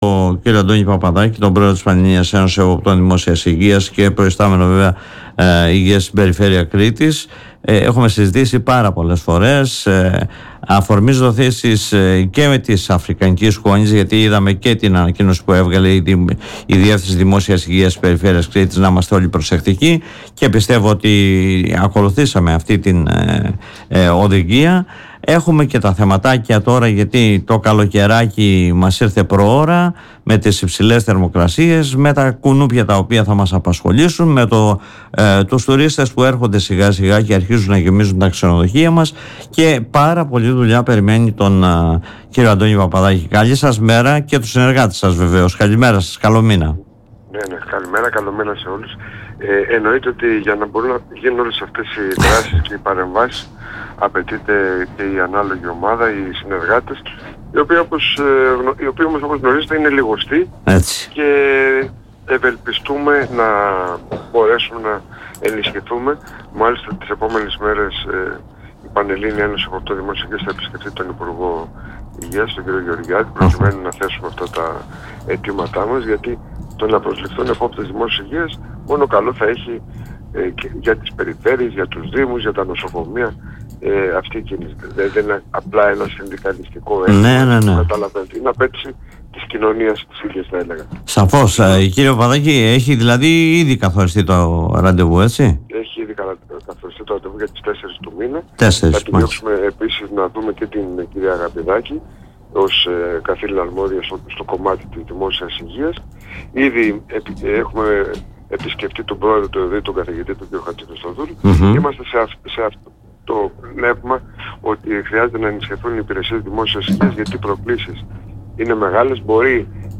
όπως δήλωσε μιλώντας στον politica 89.8